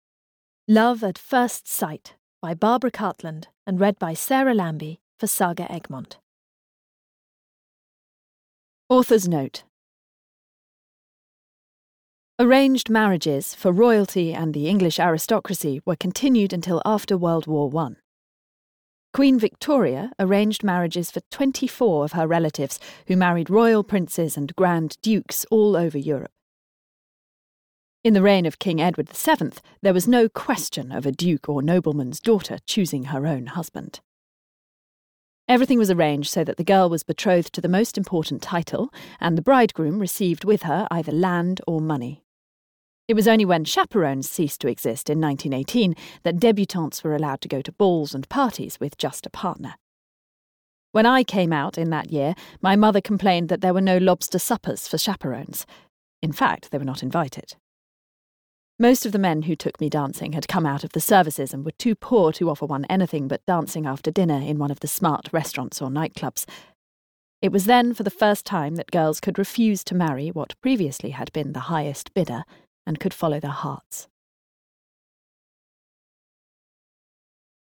Love at First Sight (EN) audiokniha
Ukázka z knihy